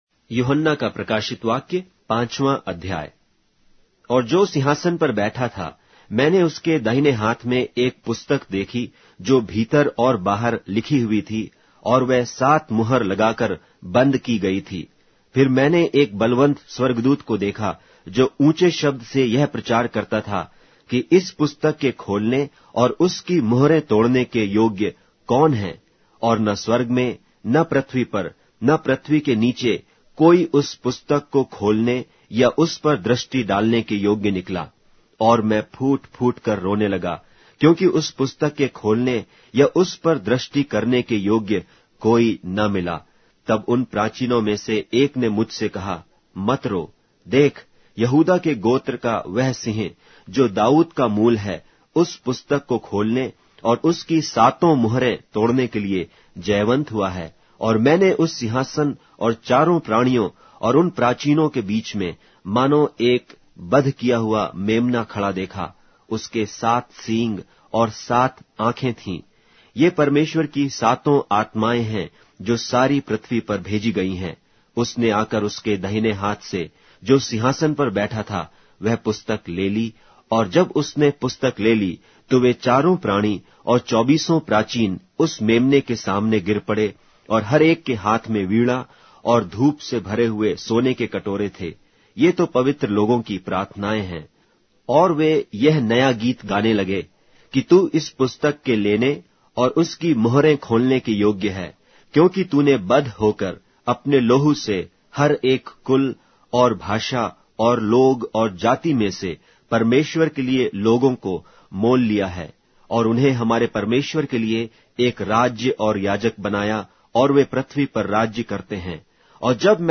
Hindi Audio Bible - Revelation 10 in Tov bible version